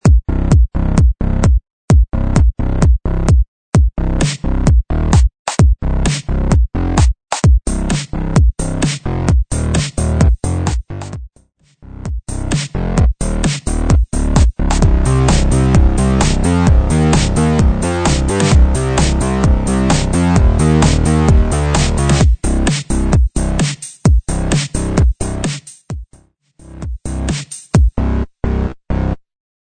130 BPM
Electronic